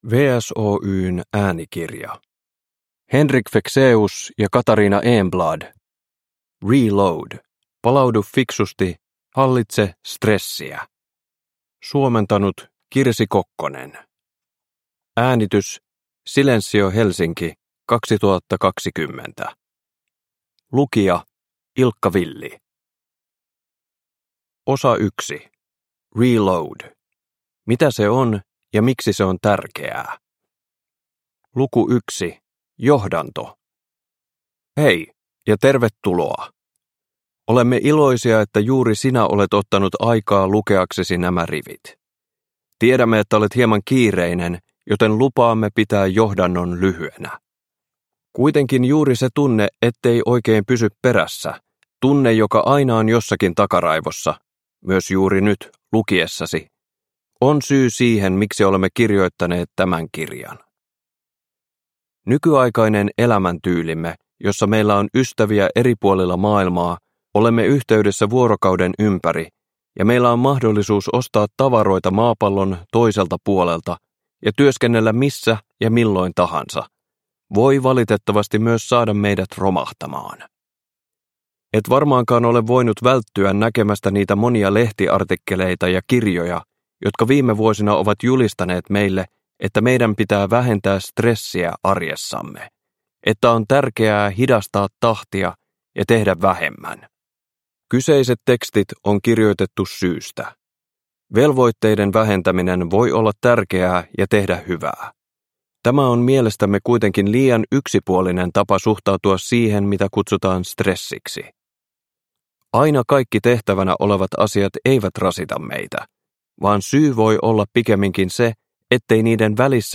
Reload – Ljudbok – Laddas ner